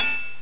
.11open_Tri.mp3